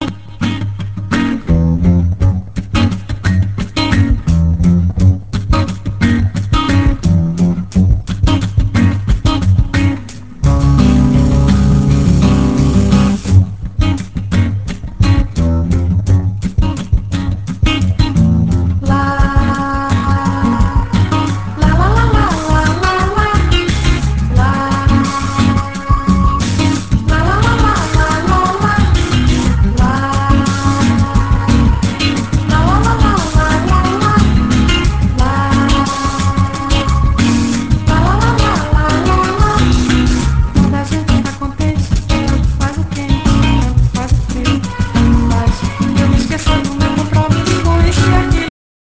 avec une rythmique vocale assez remarquable.